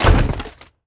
01_woodendoor.wav